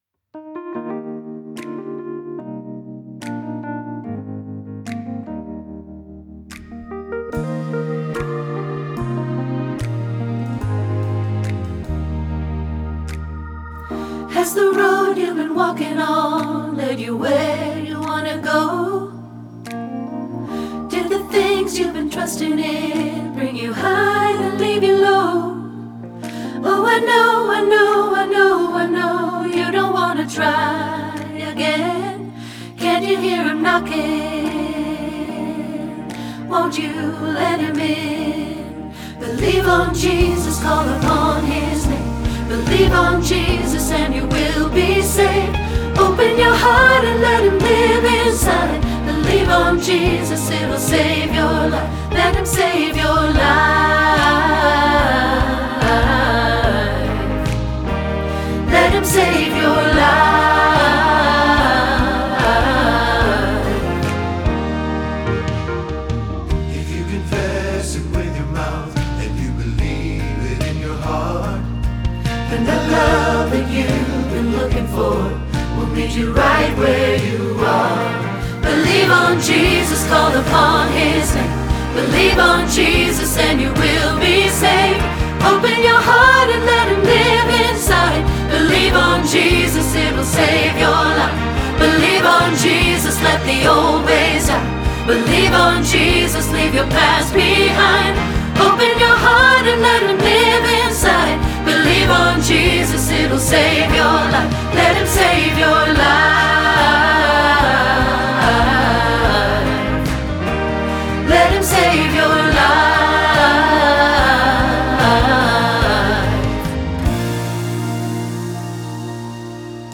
Believe on Jesus – Alto – Hilltop Choir
Believe-on-Jesus-Alto.mp3